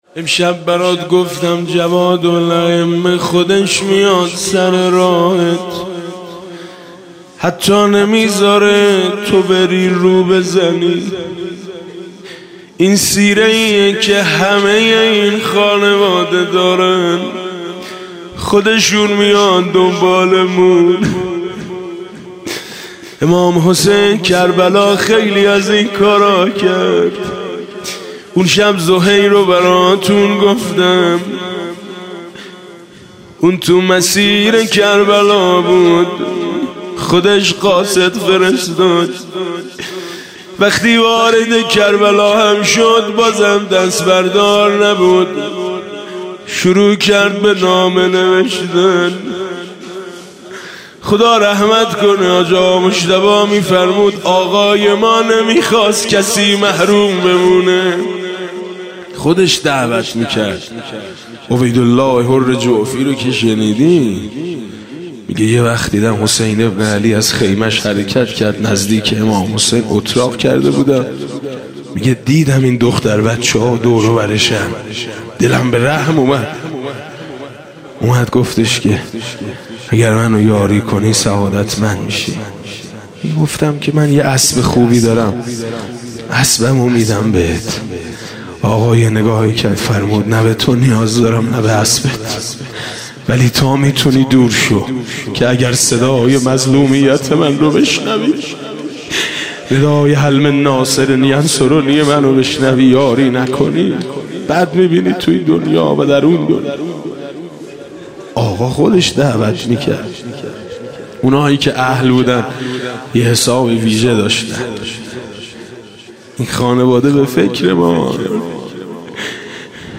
دانلود دعا و مناجات خوانی حاج میثم مطیعی – شب هشتم ماه مبارک رمضان۹۷ – مجله نودیها